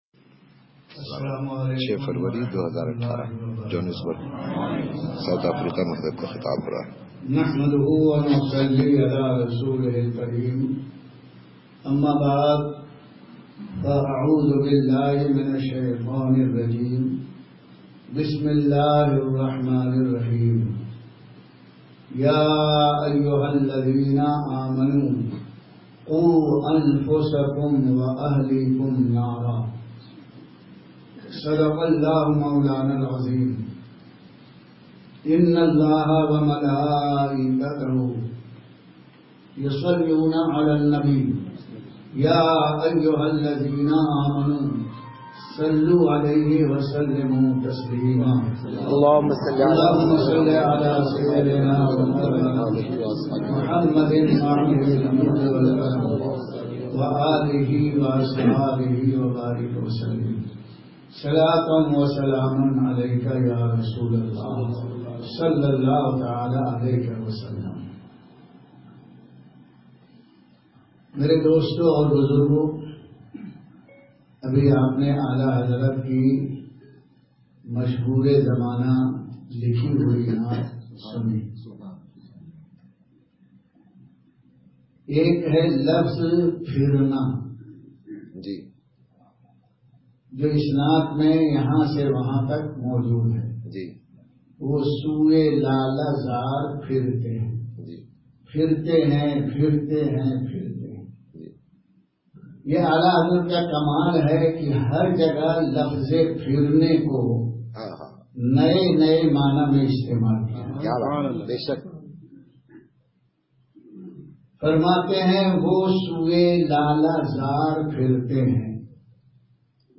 Speeches